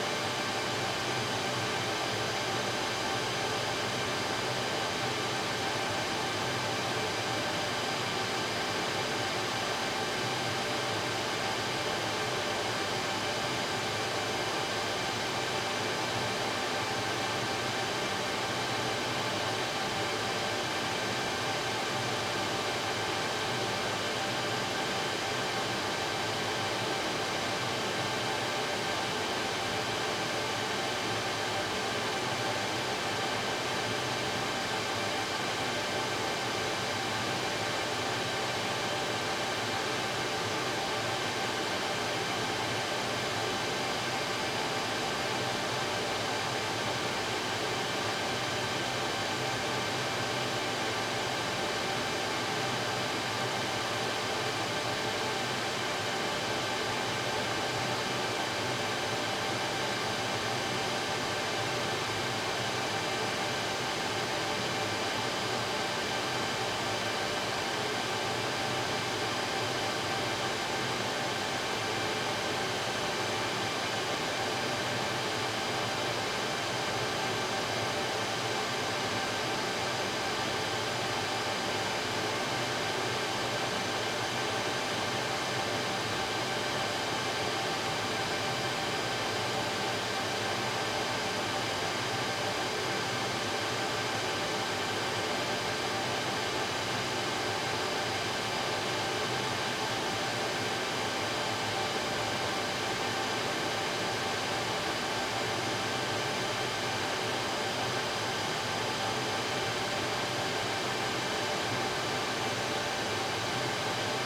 DeltaVR/Ambience, Room Tone, Server Room, Data Center, Electronic Whirr and Hum, Air Condition, HVAC 02 SND144438.wav at SamWorkset